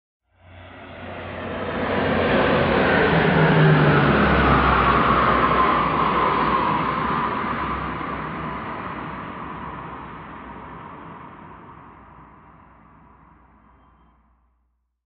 AIRCRAFT PROP TWIN TURBO: EXT: Fly by medium fast speed.